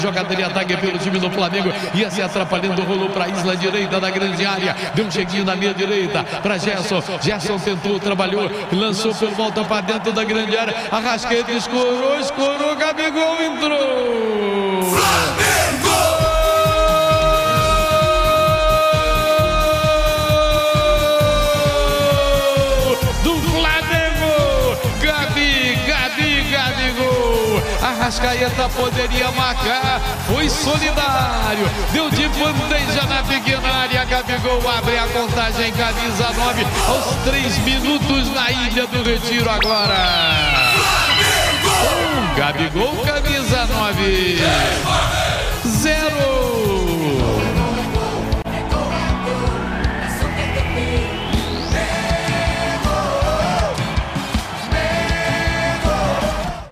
Ouça os gols da vitória do Flamengo em cima do Sport, na voz de José Carlos Araújo